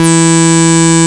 74.04 BASS.wav